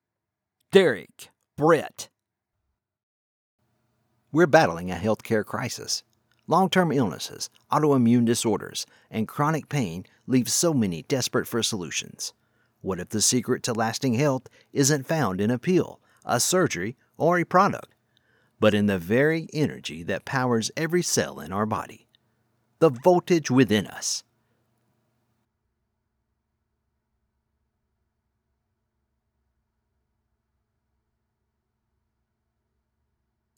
Male North American Southern Voice
Character Video Game Animation
Southern North America, South East North America, Alabama, General North America, Generic North America, Dixie Delta